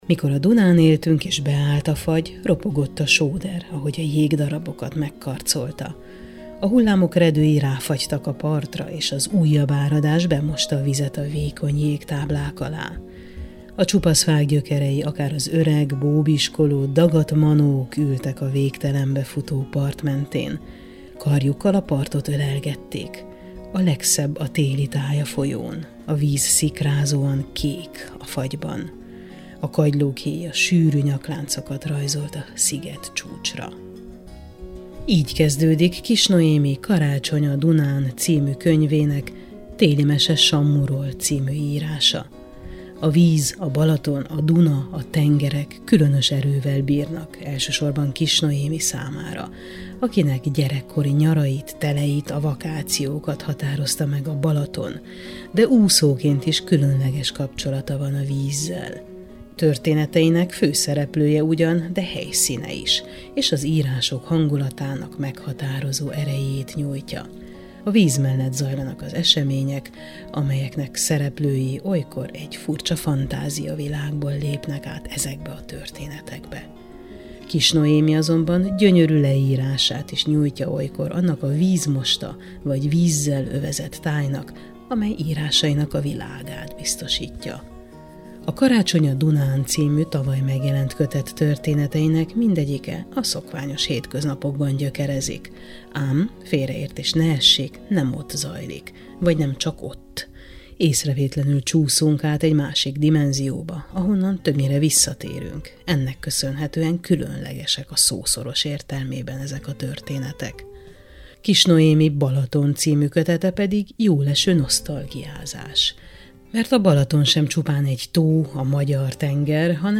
Beszélgetés